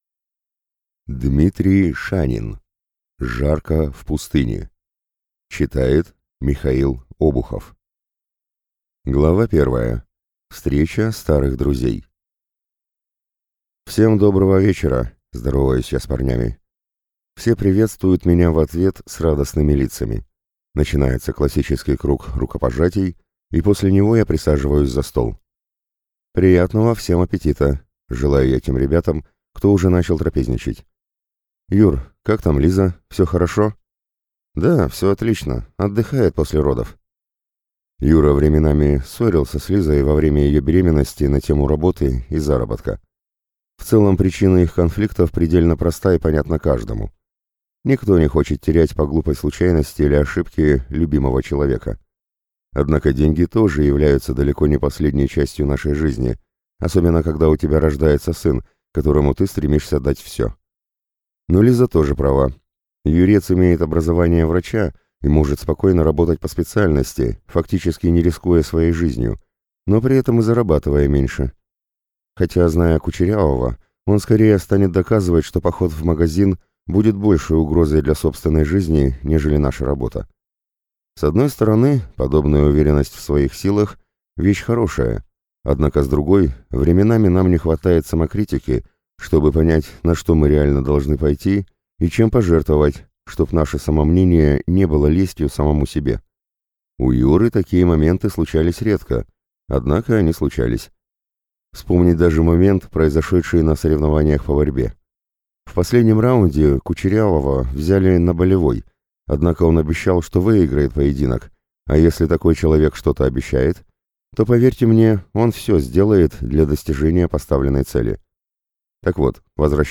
Аудиокнига Жарка в пустыне | Библиотека аудиокниг